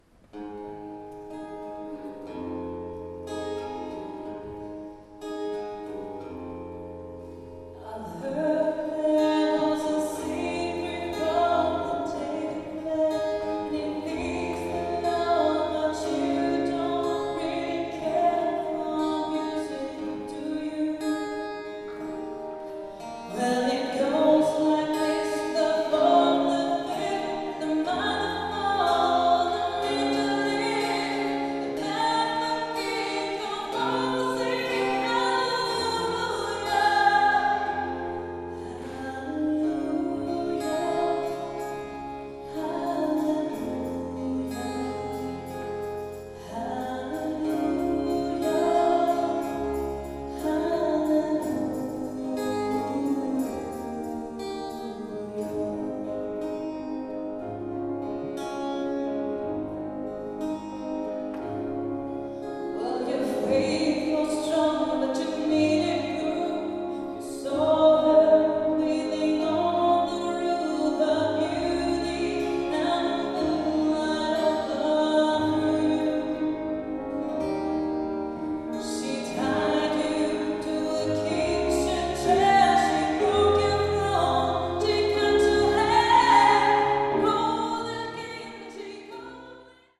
live Quartett